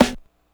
snare02.wav